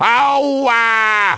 Wario_(OWWAAAHHH)_-_Mario_Kart_Double_Dash.oga